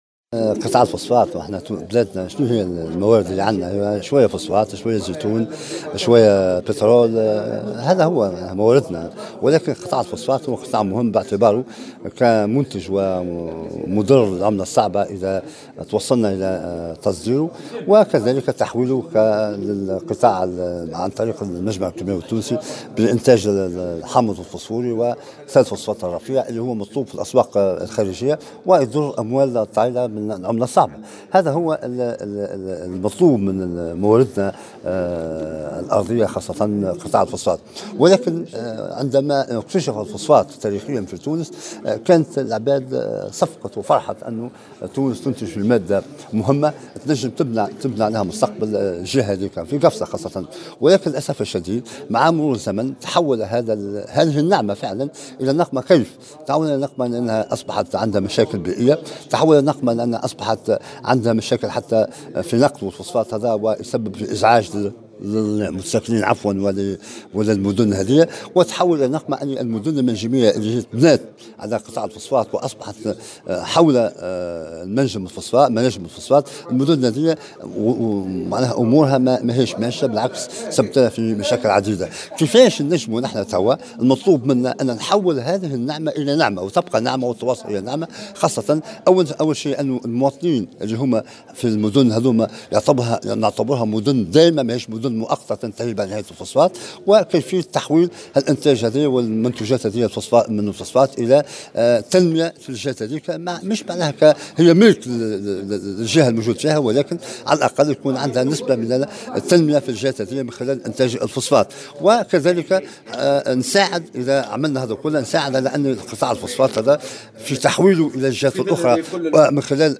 في تصريح لمراسلة الجوهرة أف أم